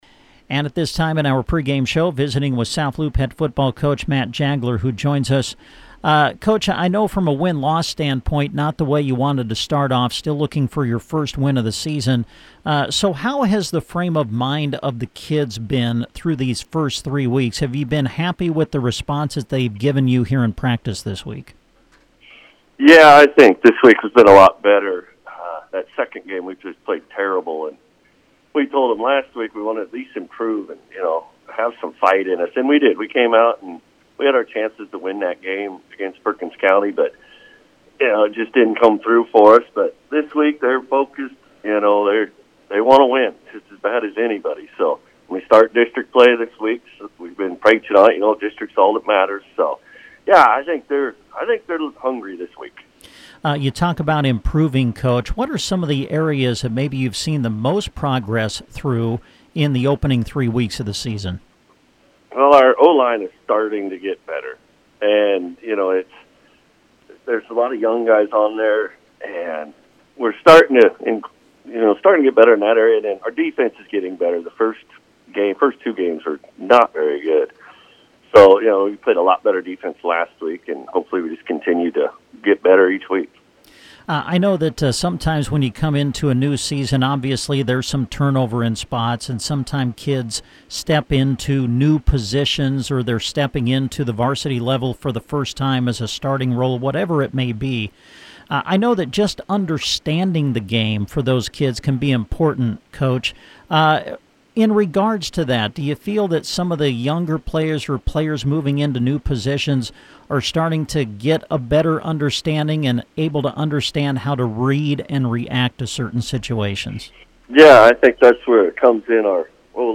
The interviews are posted below.